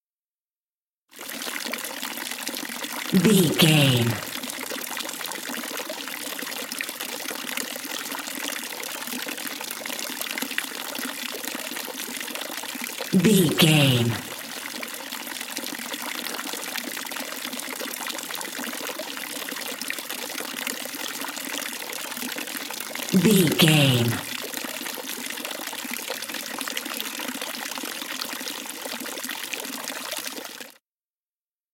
Fountain small ambience
Sound Effects
ambience